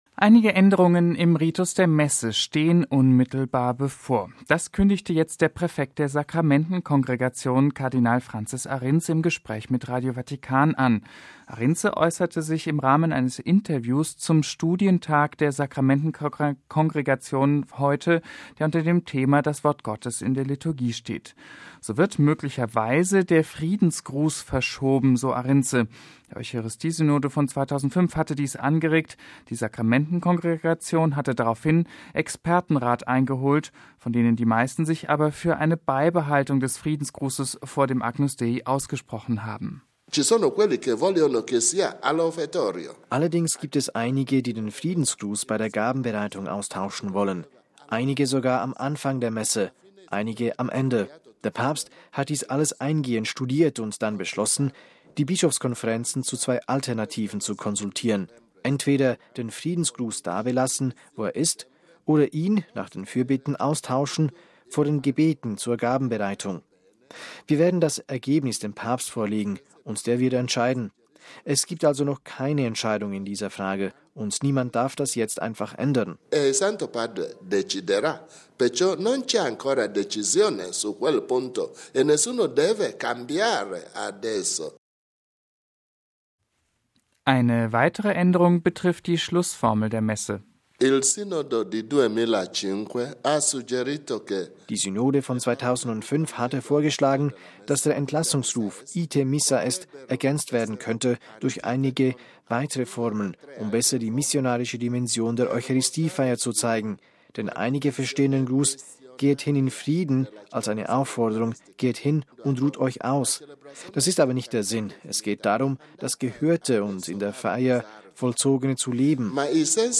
MP3 Einige Änderungen im Ritus der Messe stehen unmittelbar bevor. Das kündigte jetzt der Präfekt der Sakramentenkongregation, Kardinal Francis Arinze, im Gespräch mit Radio Vatikan an.